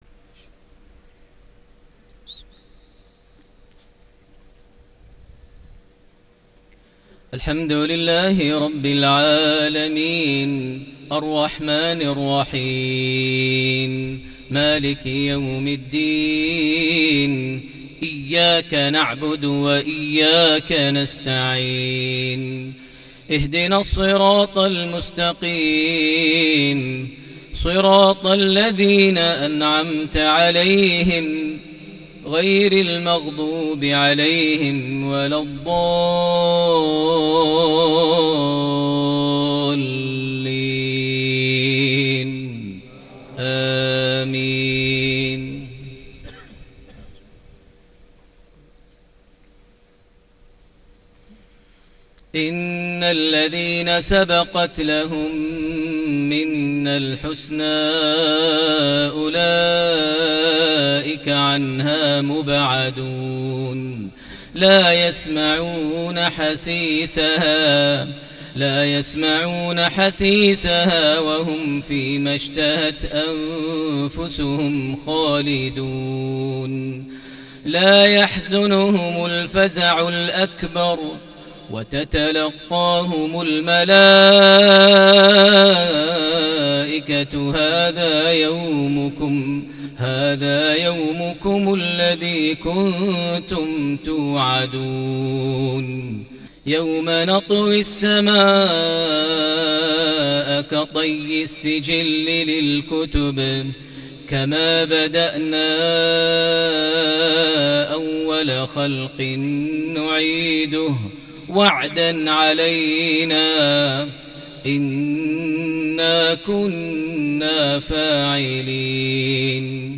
صلاة المغرب ٨ صفر ١٤٤٠هـ خواتيم سورة الأنبياء > 1440 هـ > الفروض - تلاوات ماهر المعيقلي